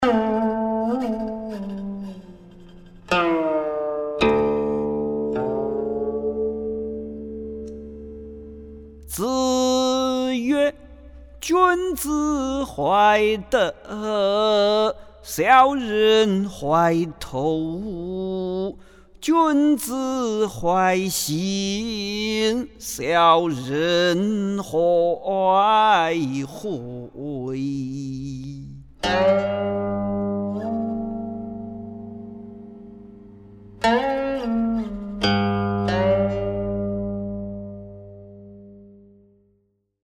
誦唸